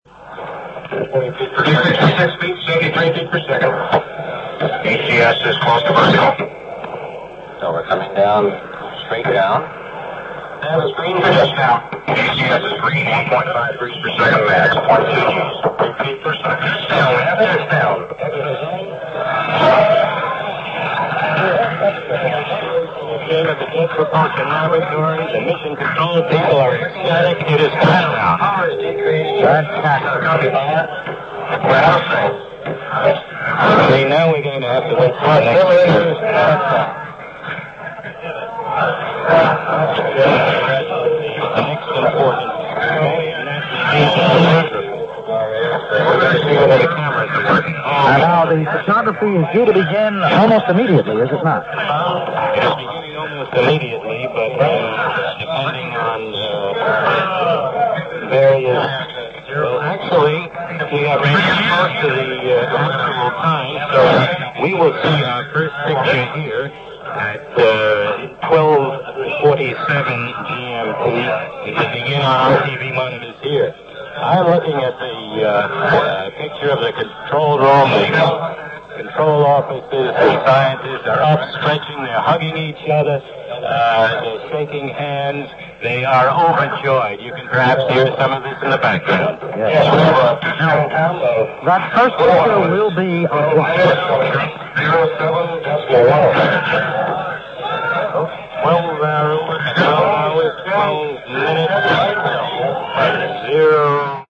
Recorded off-air from The Voice of America.
The audio quality is quite poor, but the excitement is impossible to hide:
Viking_1_landing_VOA.mp3